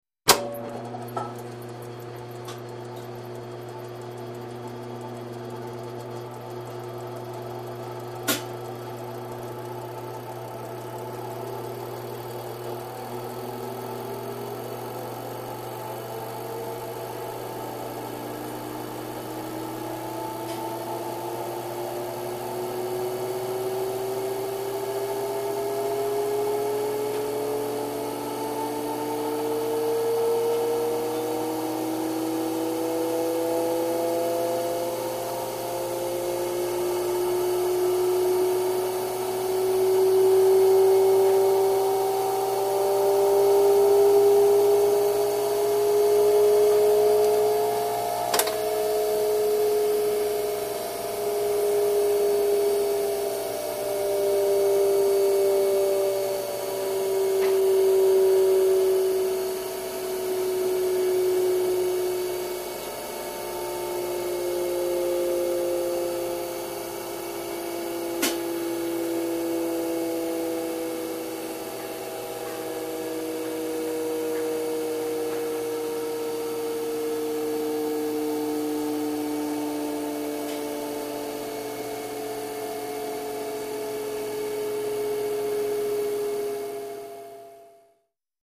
Centrifuge; On / Off 2; Centrifuge; Click On / Slowly Spin Up ( Various Background Clicks ) / Click Off / Slowly Spin Down, Close Perspective. Medical Lab.